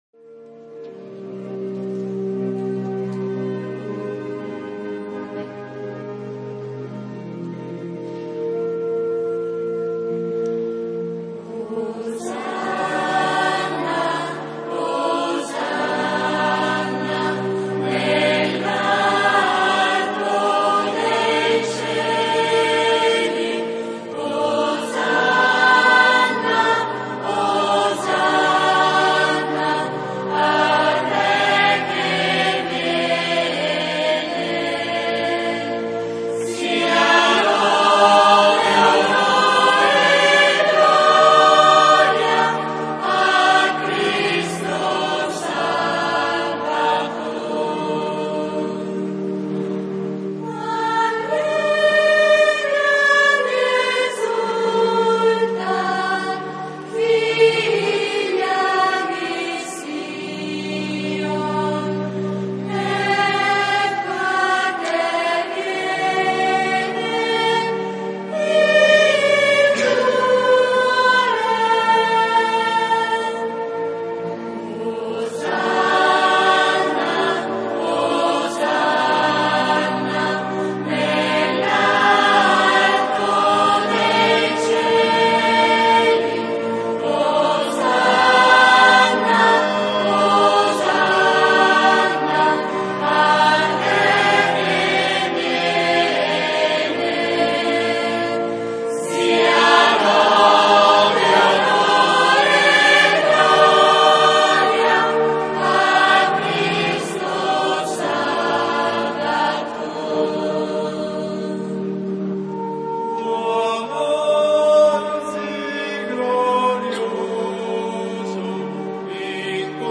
DOMENICA DELLE PALME
canto: